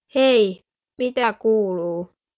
Spraaksynthese met de stem van een kind
Otto’s stem is gemodelleerd naar een 9-jarige jongen, maar met de veelzijdige stembedieningsinstellingen van DialoQ Speech kan deze worden aangepast aan oudere jongens en jongere meisjes.
Otto 13j (Fins):